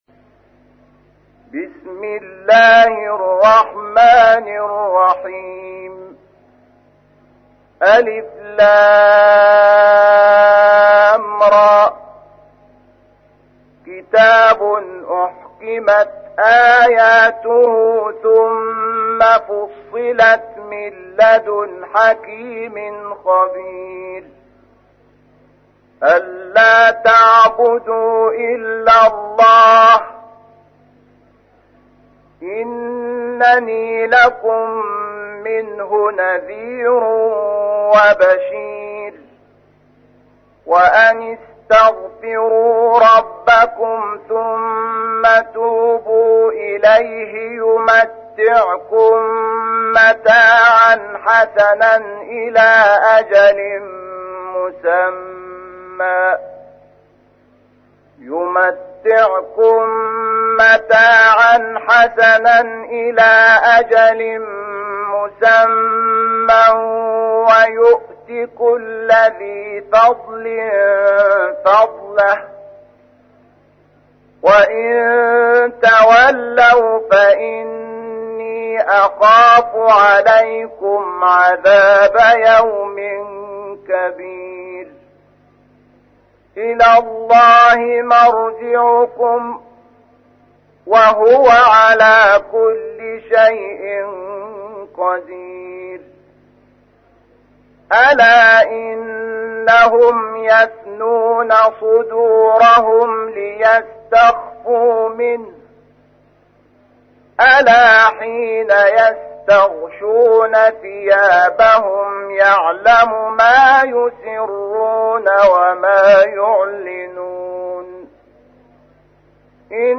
تحميل : 11. سورة هود / القارئ شحات محمد انور / القرآن الكريم / موقع يا حسين